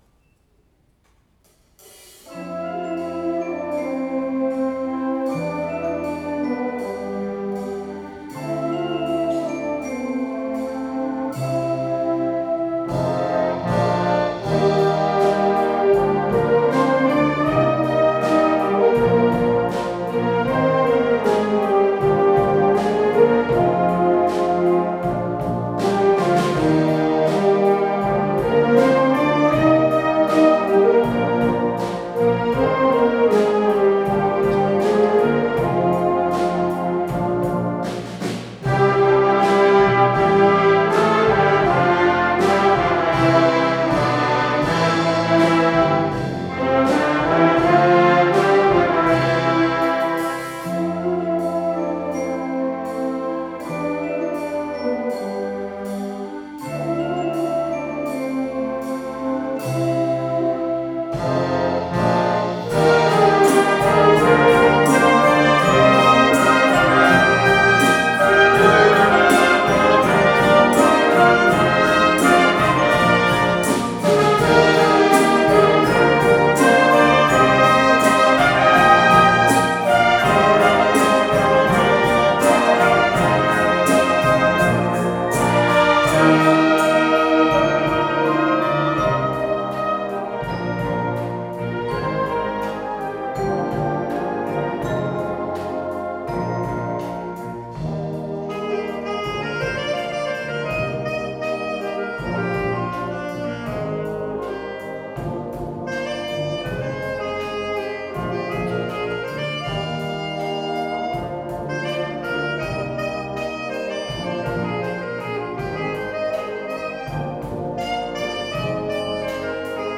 ５回目の音楽会
8月　鈴鹿市民会館
大地の躍動 　後半は、OBとの合同も含め、音楽中心の舞台です。